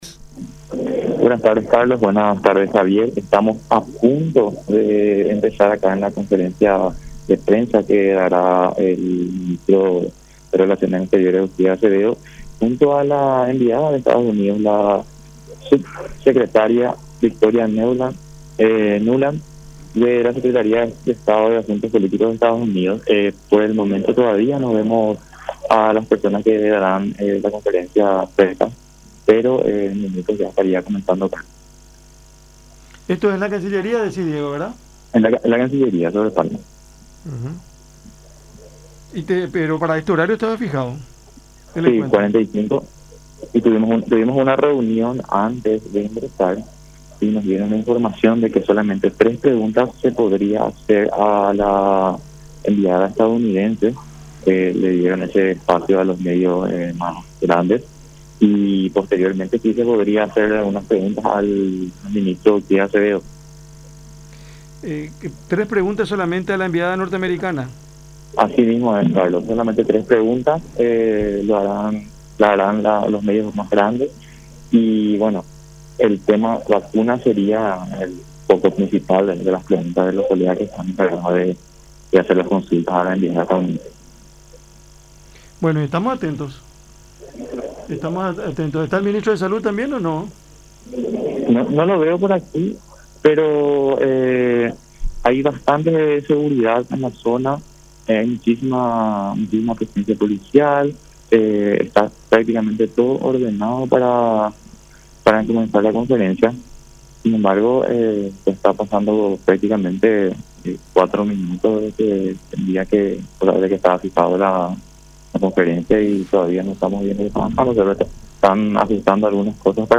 A su vez, el ministro de Relaciones Exteriores, Euclides Acevedo, quien encabezó la conferencia de prensa, destacó el acuerdo de “solidaridad y compromiso” firmado por el gobierno norteamericano.